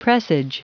Prononciation du mot presage en anglais (fichier audio)
Prononciation du mot : presage